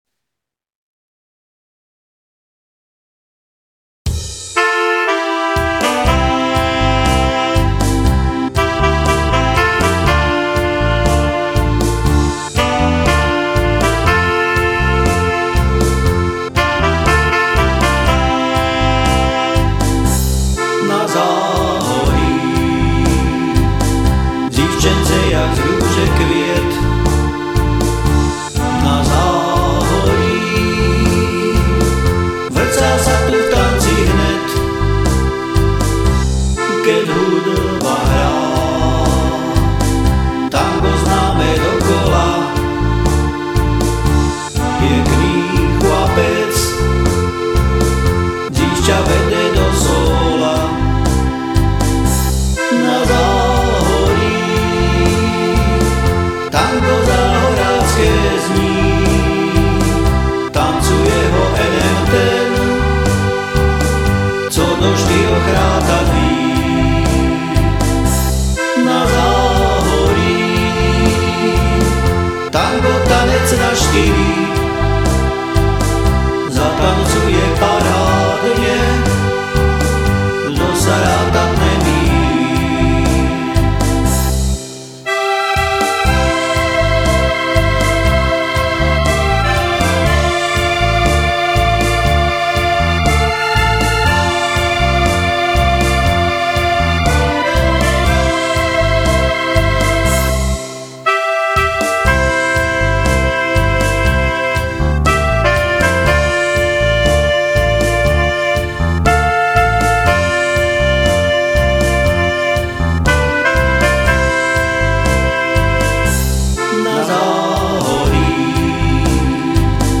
Som amatérsky muzikant, skladám piesne väčšinou v "záhoráčtine" a tu ich budem prezentovať.